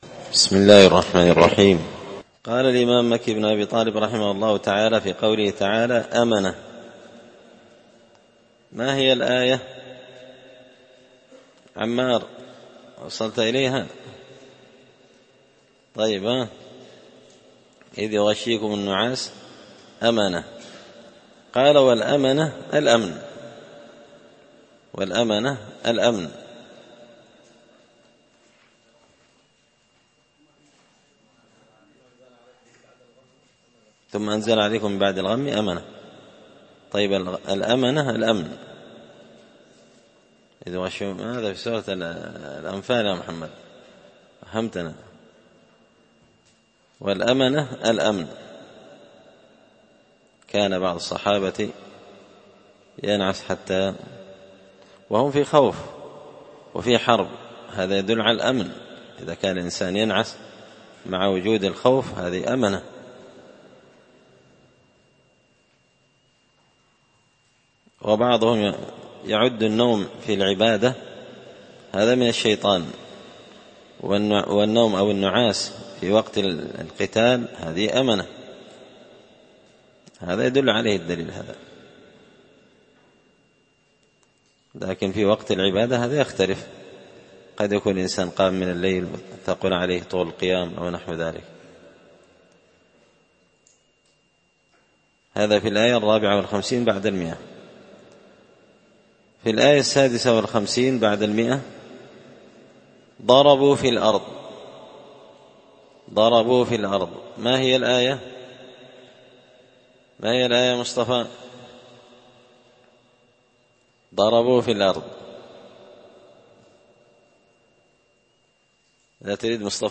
تفسير المشكل من غريب القرآن ـ الدرس 76